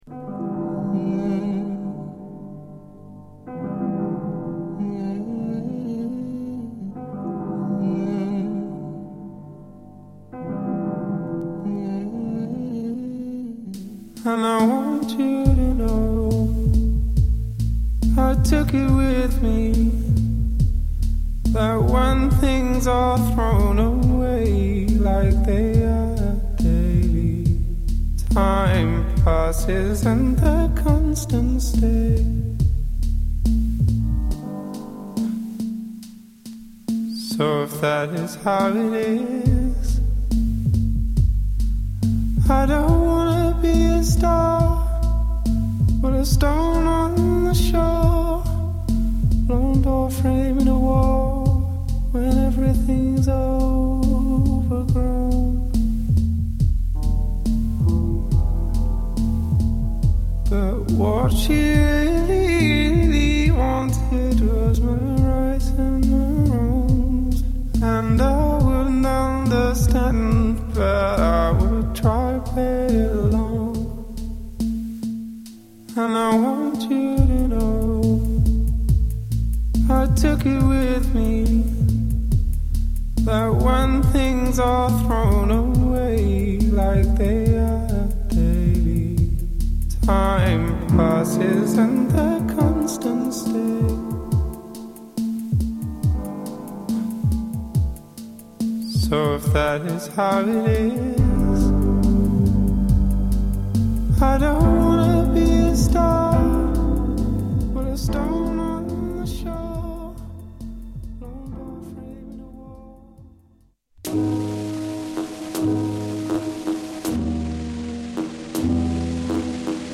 「エレクトロニックとソウルの融合」